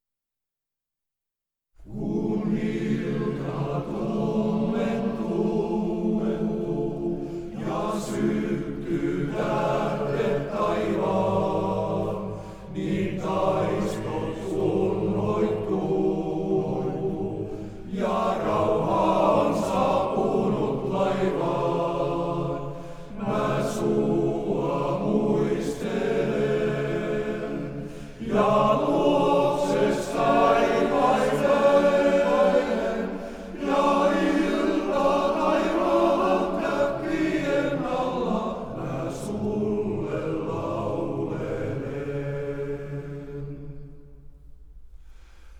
Serenadeja ja viihdelauluja